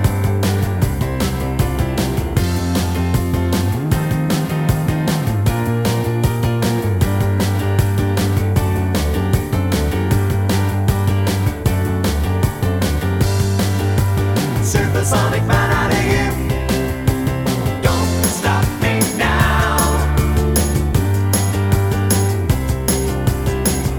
No Guitar Solo Rock 3:40 Buy £1.50